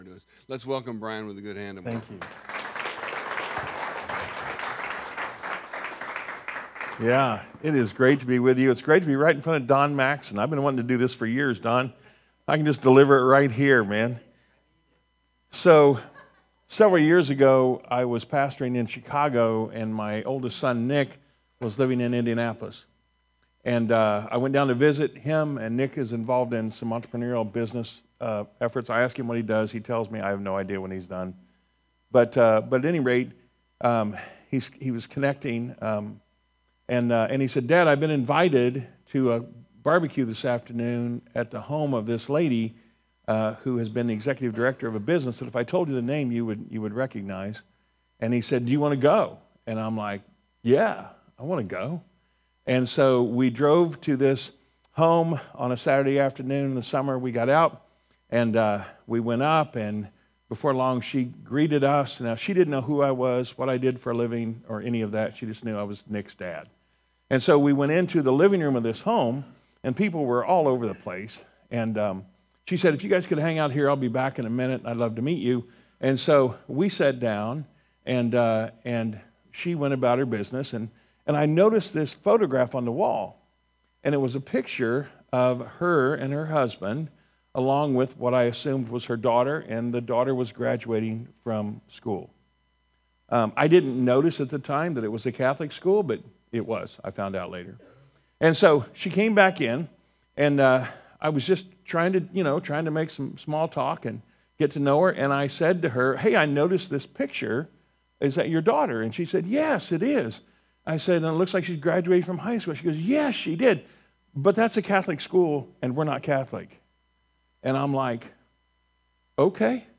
Hear recorded versions of our Sunday sermons at your leisure, in the comfort of your own personal space.